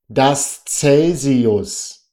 ดัส เซล-ซี-อุส